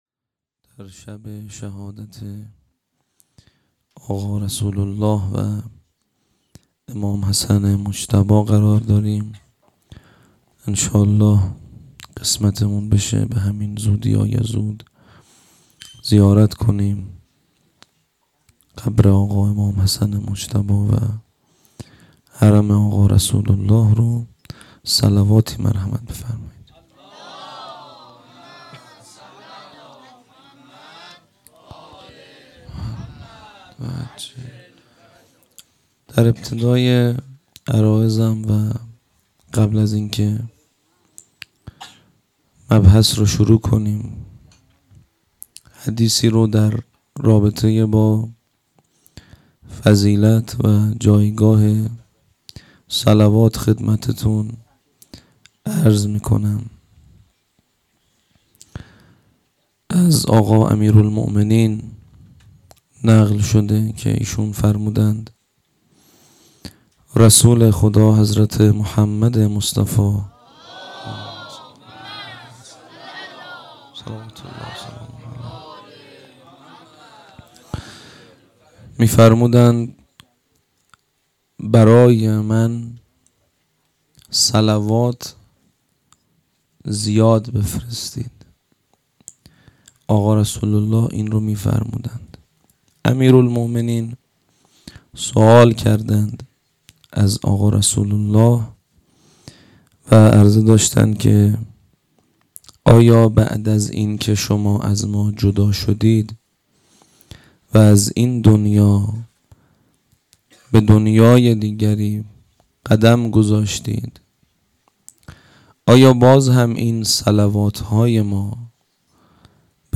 هيأت یاس علقمه سلام الله علیها